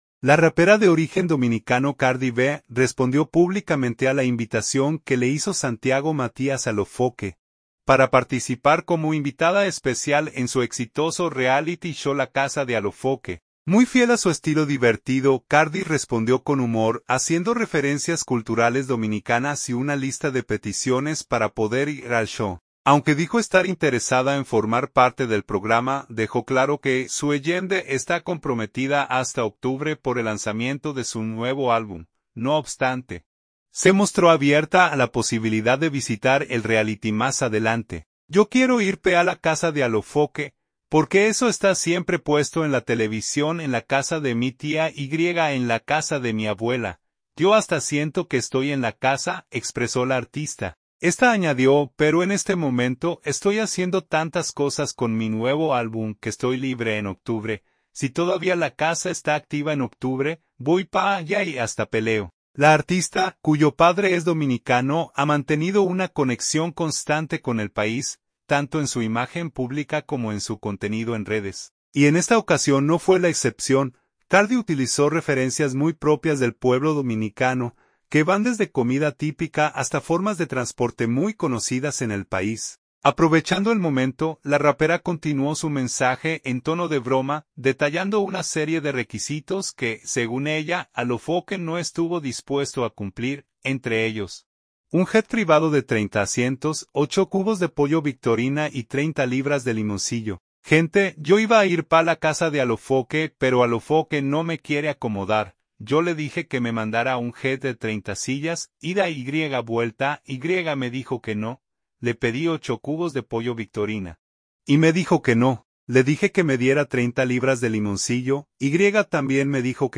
Muy fiel a su estilo divertido, Cardi respondió con humor, haciendo referencias culturales dominicanas y una lista de peticiones para poder ir al show.
Aprovechando el momento, la rapera continuó su mensaje en tono de broma, detallando una serie de “requisitos” que, según ella, Alofoke no estuvo dispuesto a cumplir.